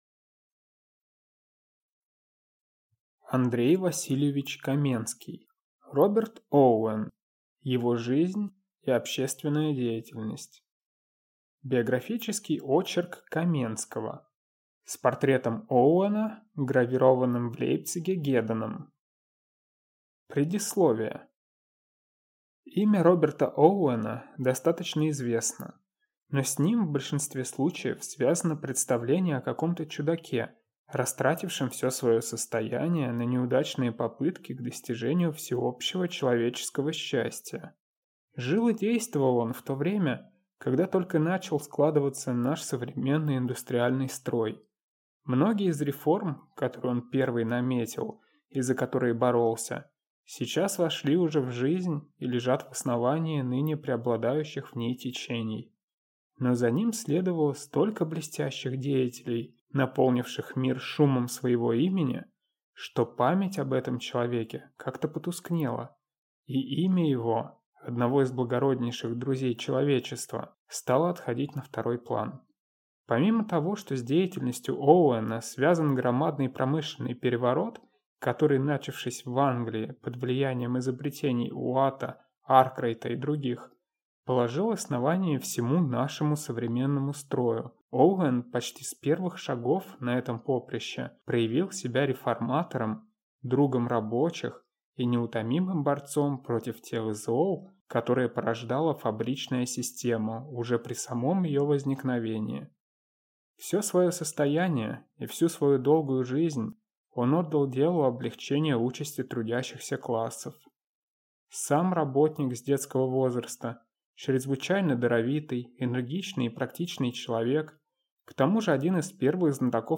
Аудиокнига Роберт Оуэн. Его жизнь и общественная деятельность | Библиотека аудиокниг